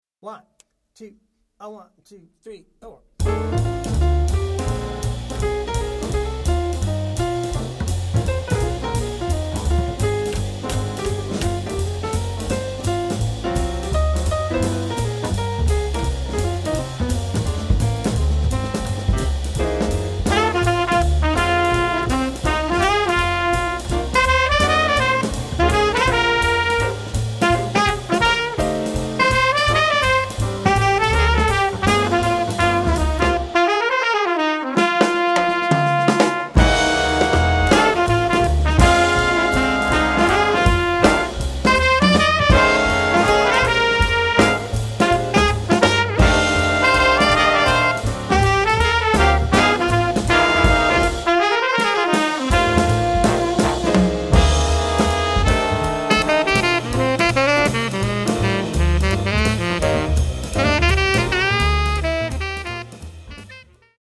Voicing: Drumset Method